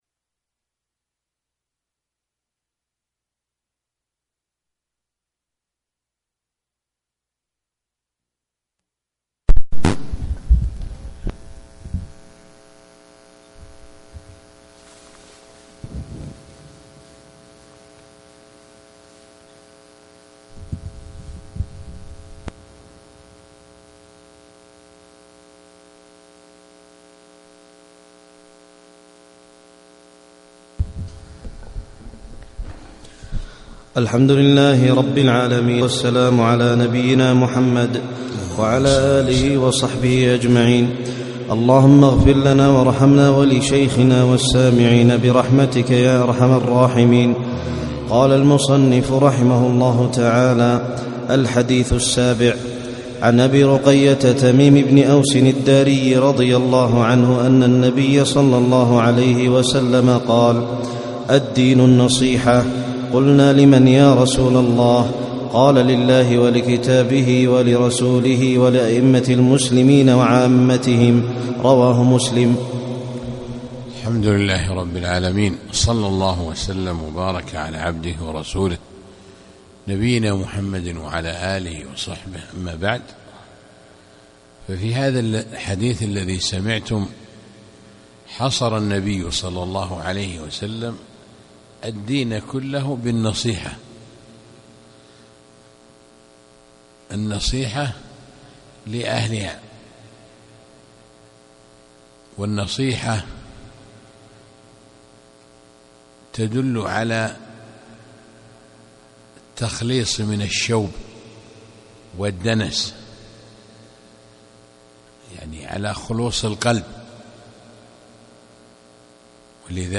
يوم السبت 18 جمادى الأولى 1437 في مسجد الشيخ